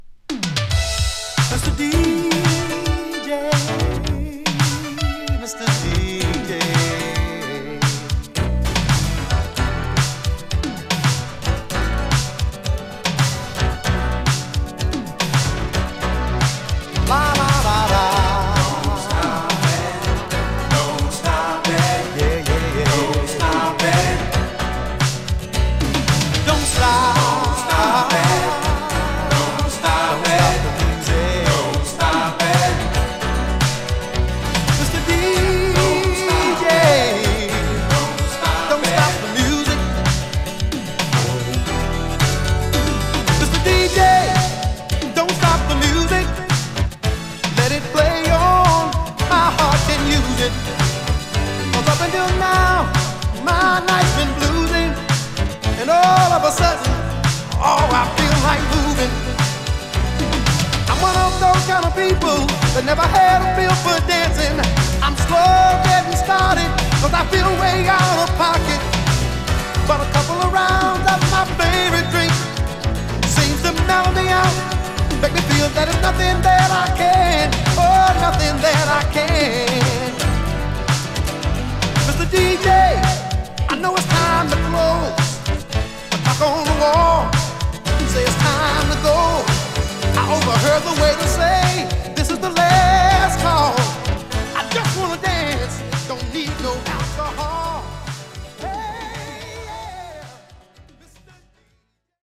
80'S ダンサー